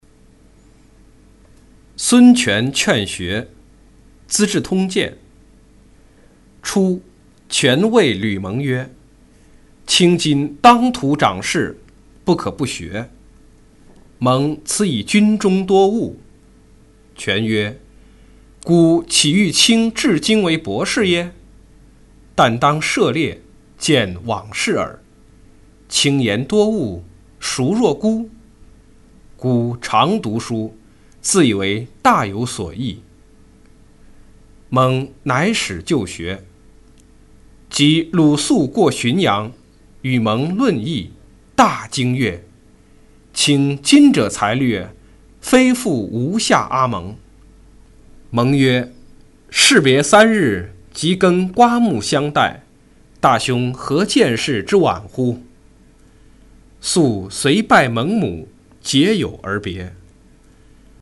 司马光《孙权劝学》原文和译文（含mp3朗读）